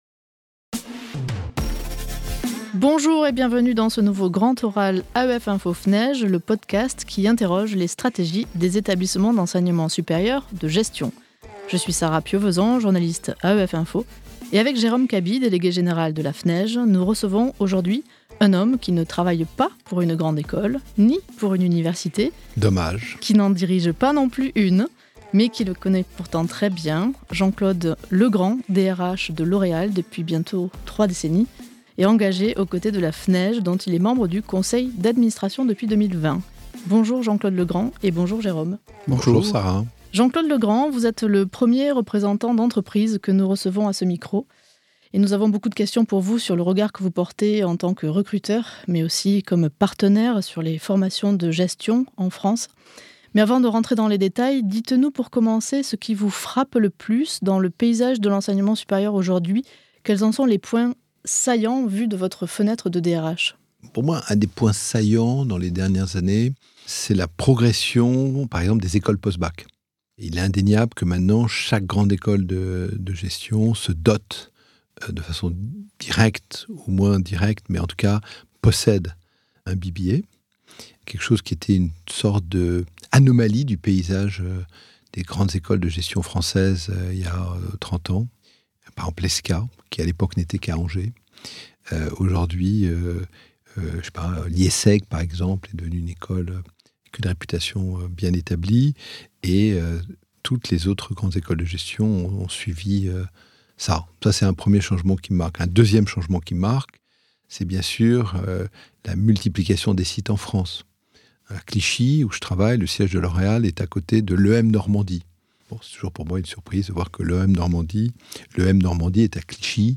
Dans ce podcast, il est interrogé sur son parcours universitaire, sur son intérêt pour la chose académique, et sur les grands sujets d'actualité : apprentissage, label, croissance du privé, ou encore diversité dans les grandes écoles.